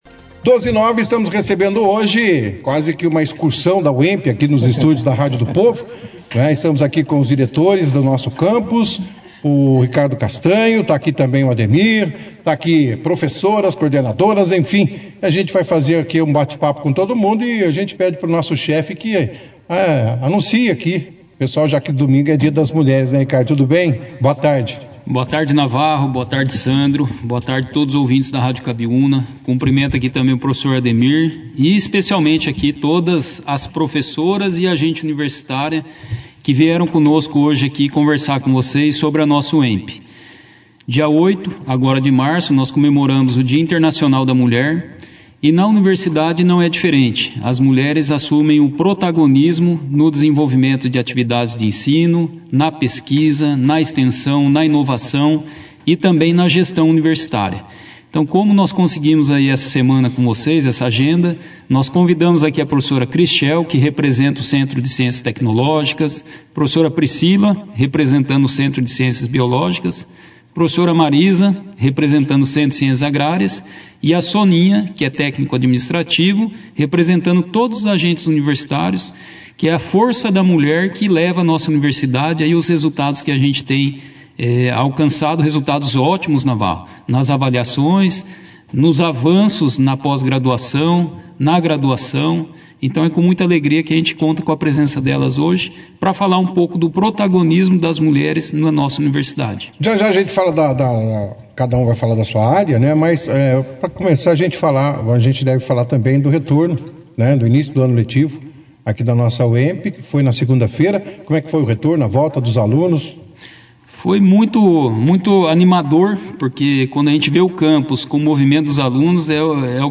E para homenagear as mulheres pelo seu dia no próximo domingo (08) eles trouxeram para o estúdio mulheres coordenadoras de cursos representando todas as mulheres da instituição.